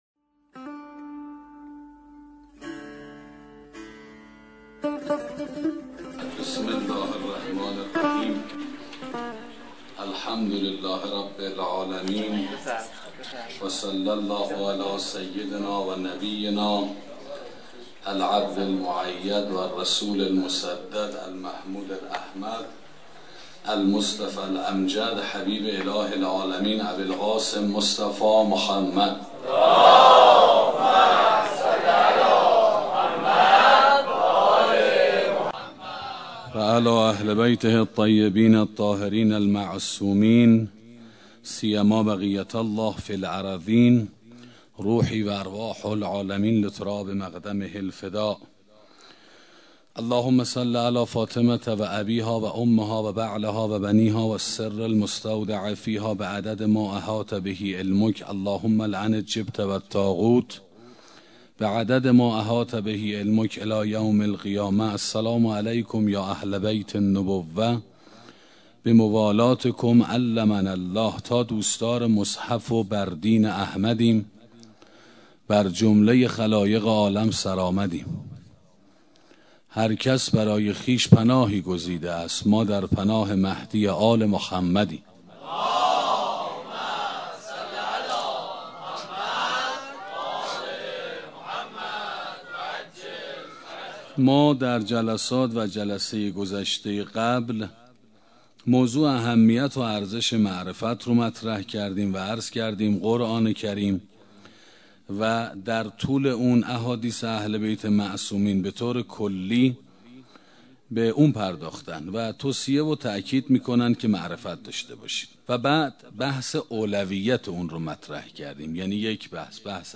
سخنرانی شناخت شخصی حضرت زهرا (س) 2